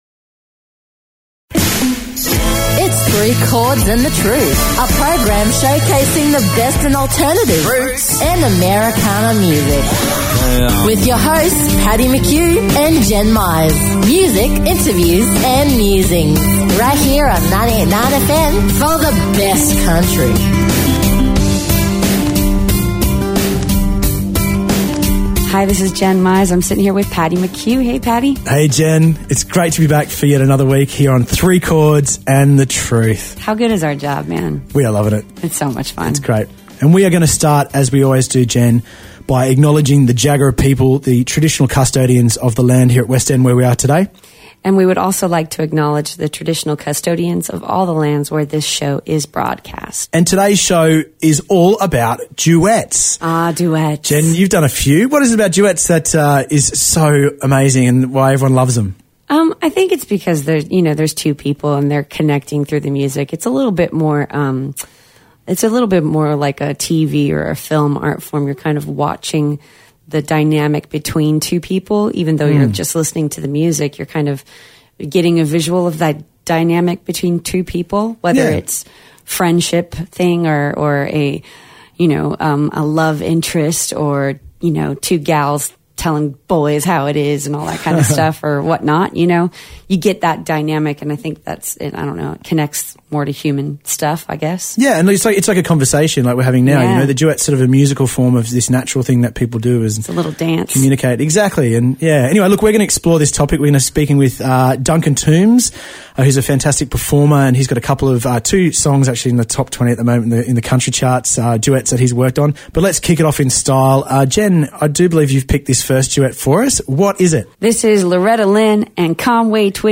Music, interviews and musings every Thursday nights from 6pm and the repeat from 10am Sunday morning.